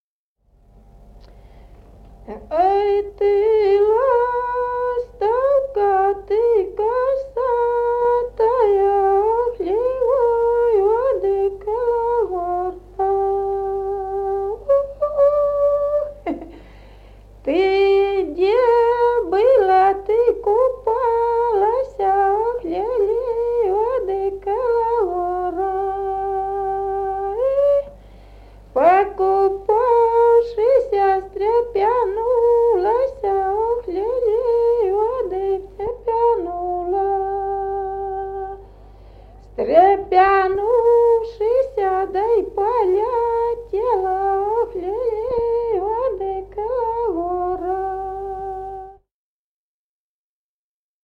Народные песни Стародубского района «Ой, ты ластовка», юрьевские таночные.
с. Мохоновка.